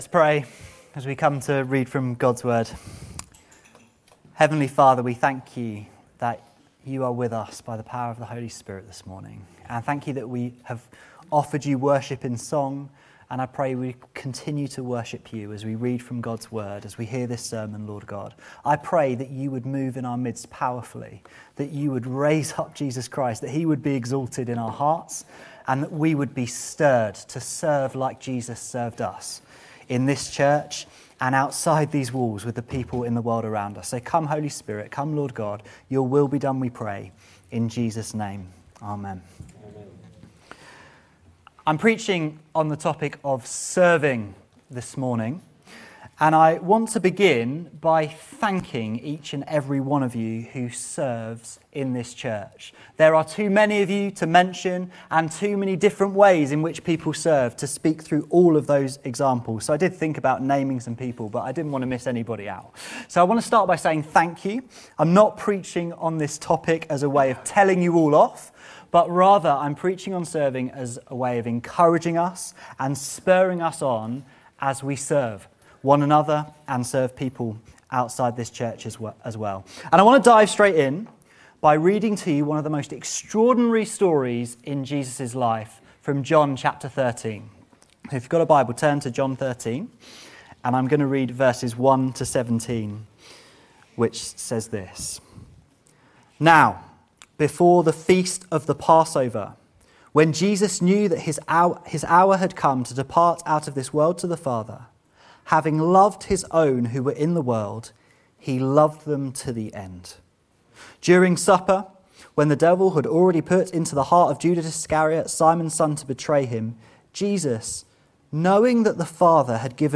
This sermon reflects on the unique and unparalleled service of Jesus Christ.